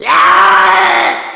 Smoker_Warn_01.wav